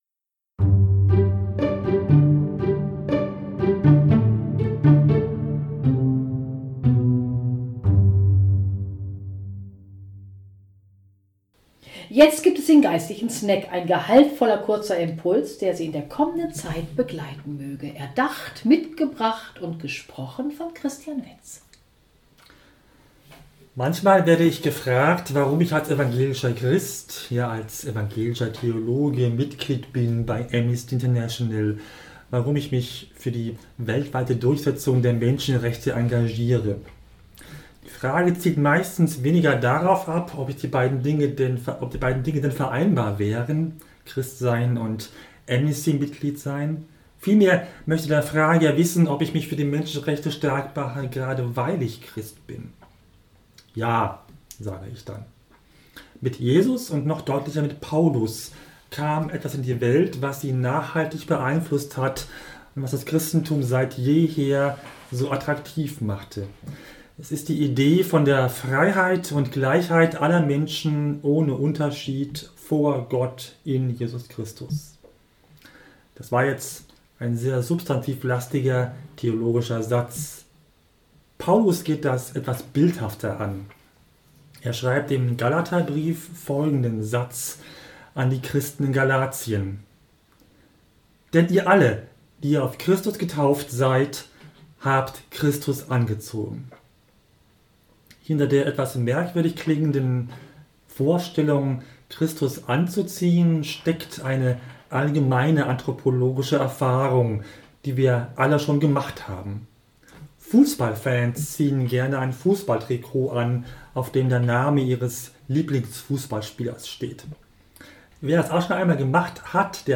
Gast im Studio